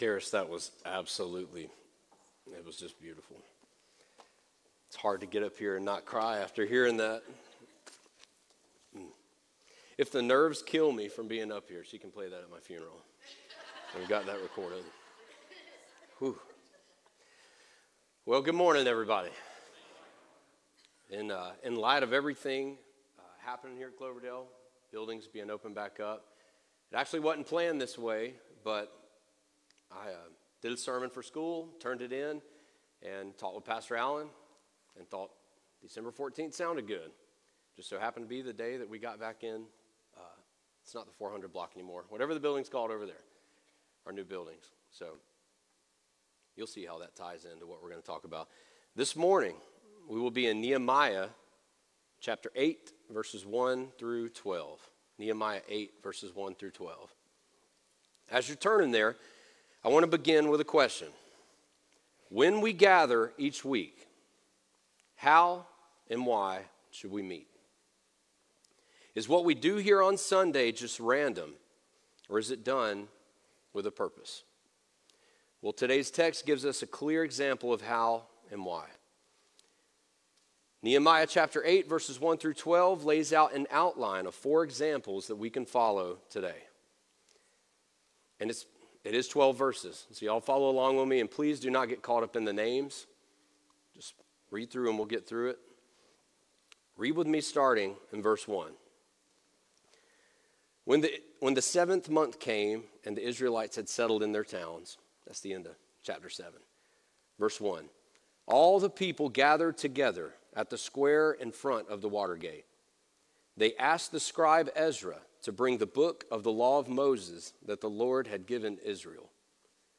Sermon Audio 12-14.m4a